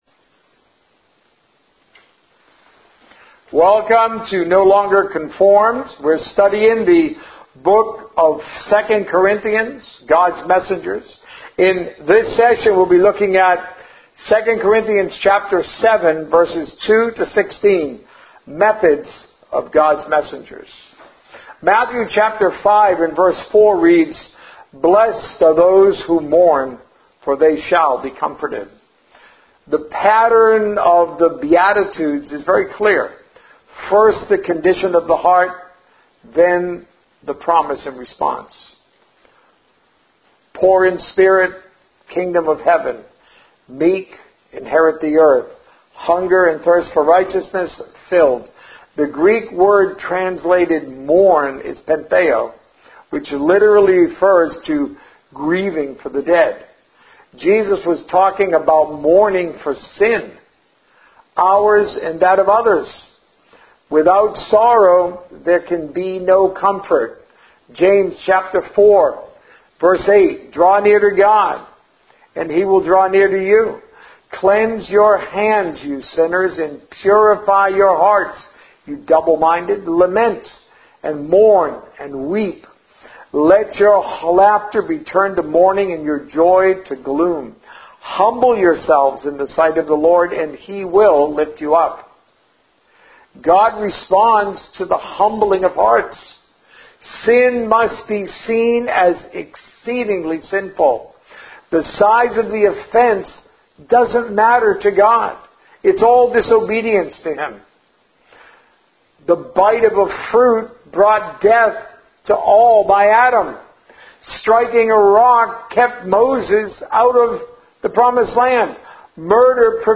Sermons - No Longer Conformed